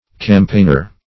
Campaigner \Cam*paign"er\, n.